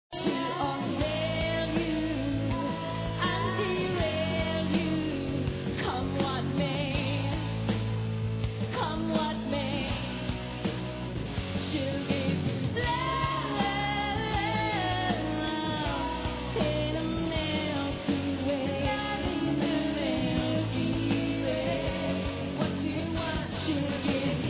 (Live in Belfast)